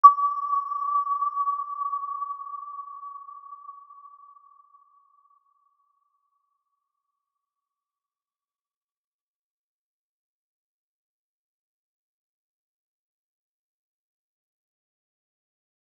Aurora-E6-mf.wav